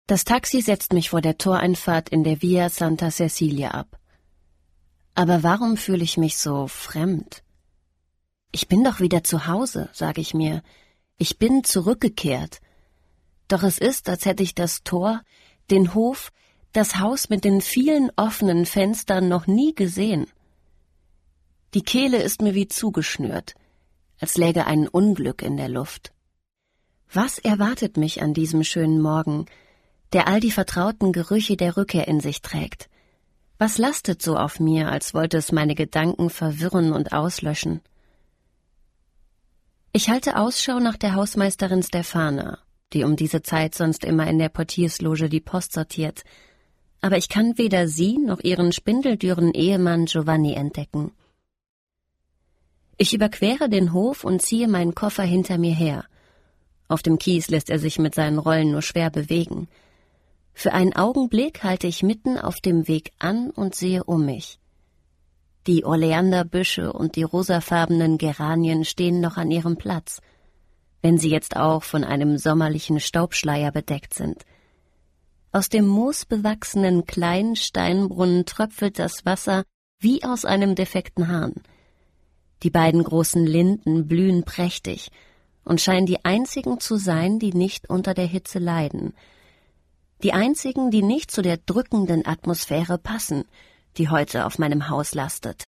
Sprachproben